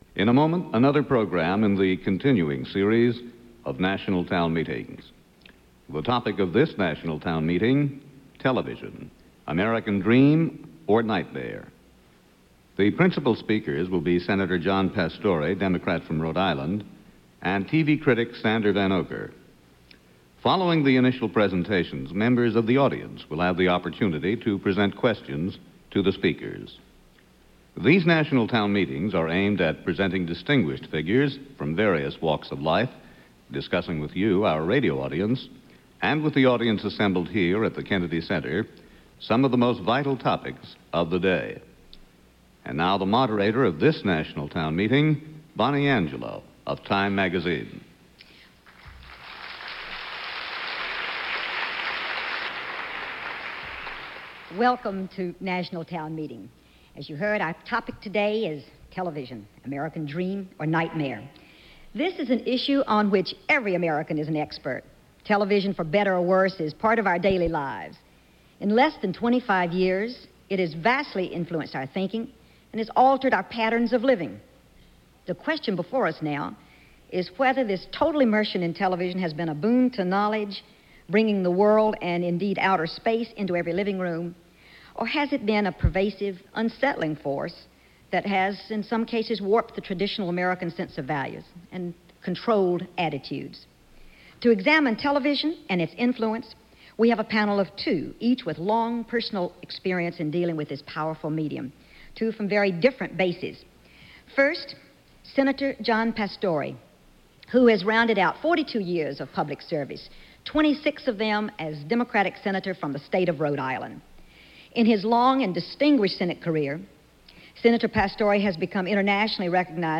TV: Dream Or Nightmare? The state of Television in America in the 1970s - National Town Meeting discussion 1976 - Past Daily Reference Room.